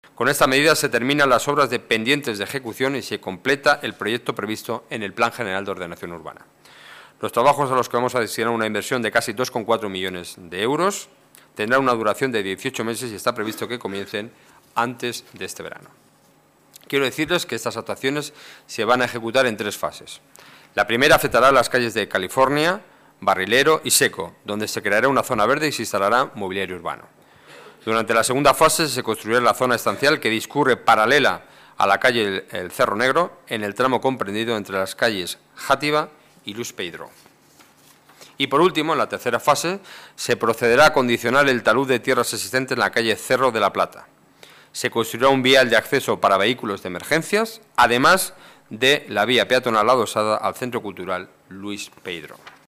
Nueva ventana:Declaraciones portavoz Gobierno municipal, Enrique Núñez: Junta Gobierno, proyecto urbanización Adelfas